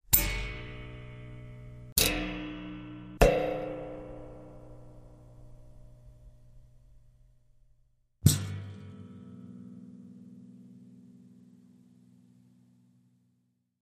Springs, Bed, Break, Tear x4